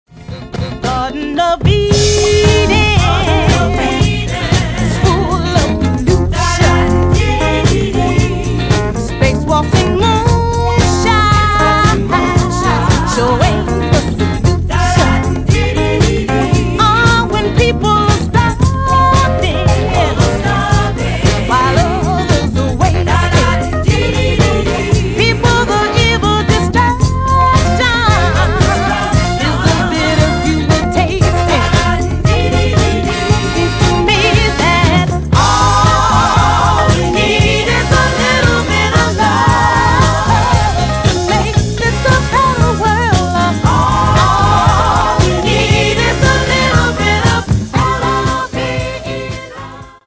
Genere:   Soul Funky